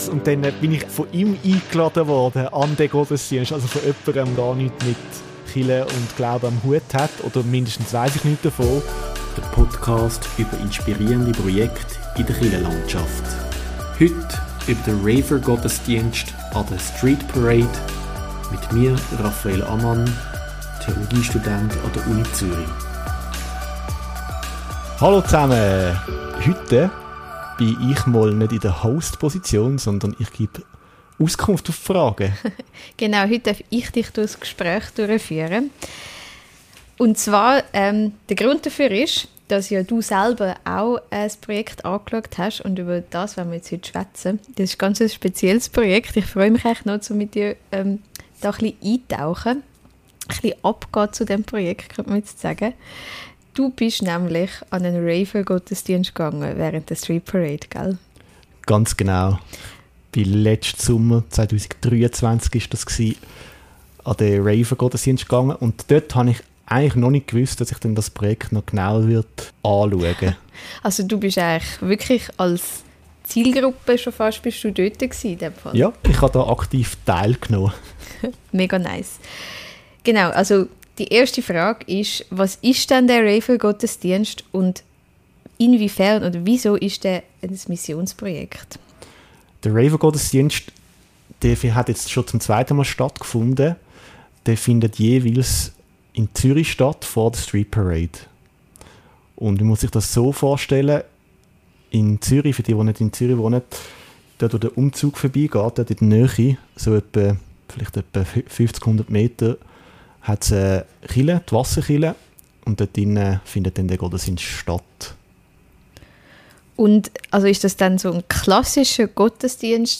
Wir diskutieren, wie Kirche damit «zu den Leuten» geht, warum Irritation Neugier wecken kann und inwiefern solch punktuelle Erlebnisse trotzdem langfristige Glaubenswege anstossen können. Ein Gespräch über Mut zur Präsenz, Gelassenheit im «Markt der Religionen» und die Freude, mit der Stadt mitzufeiern.